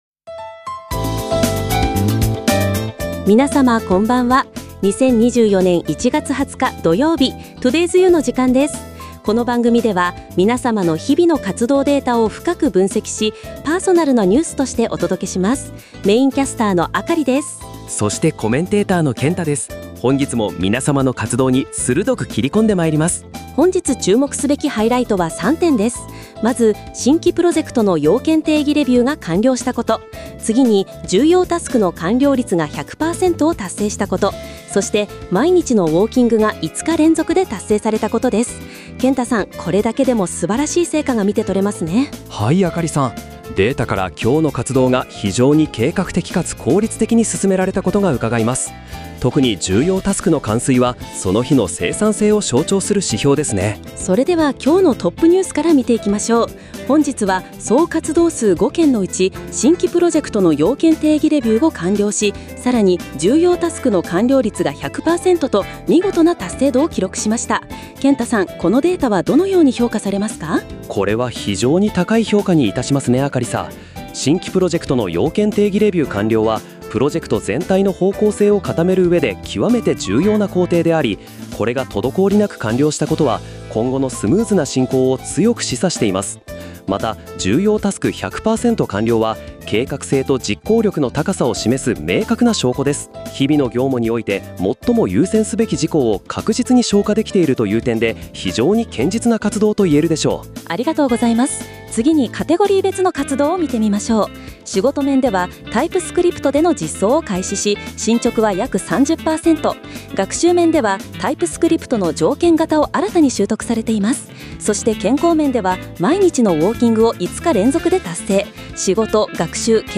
• AI パーソナリティによる分析 - 2人のキャスターが対話形式であなたの1日を紹介
• あかり（メインキャスター）: 冷静で分析的な視点から全体を俯瞰
• けんた（コメンテーター）: 洞察力のある視点で深い分析を提供
Google の最新 TTS 技術に加えて、BGMを追加することで自然で聴き心地の良い音声データを生成しています。
• Gemini APIで音声合成（TTSモデル利用）
• BGMの追加とダッキング処理